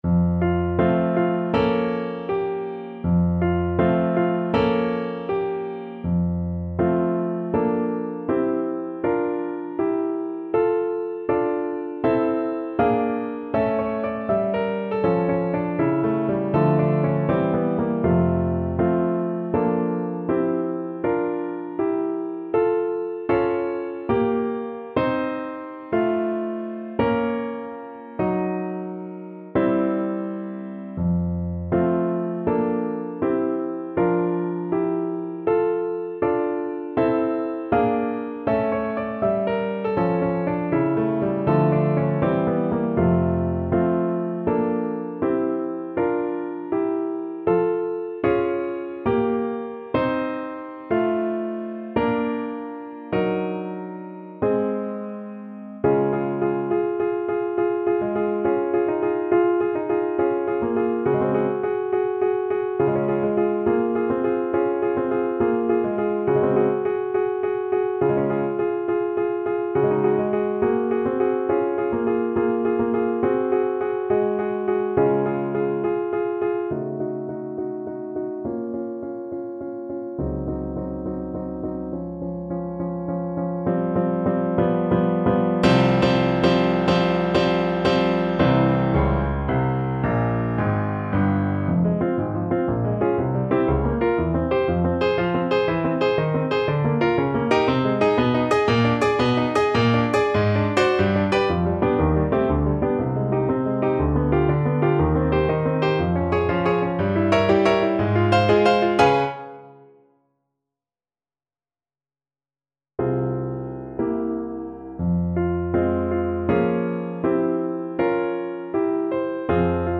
Moderato =80
Classical (View more Classical Clarinet Music)